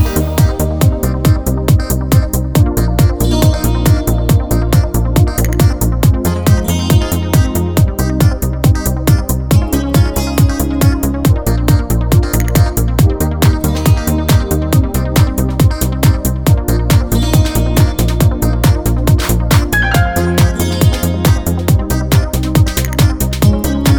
Dance Version Dance 3:55 Buy £1.50